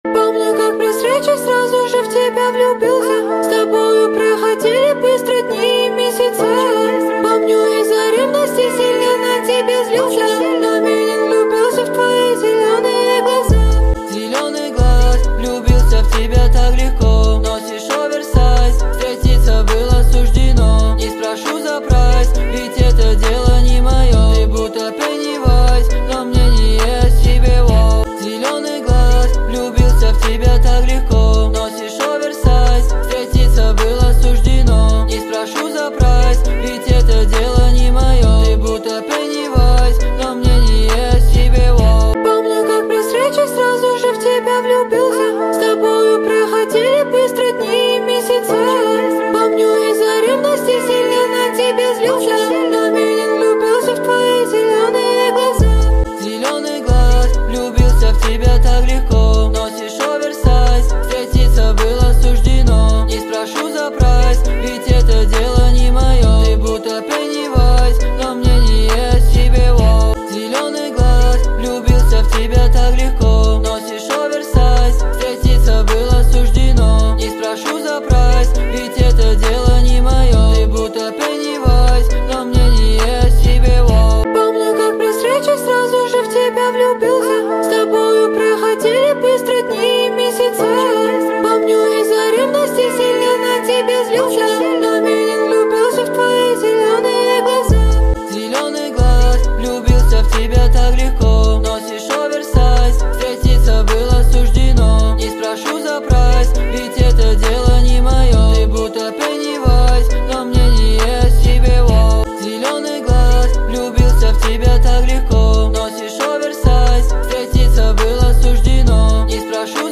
• Жанр: Русская музыка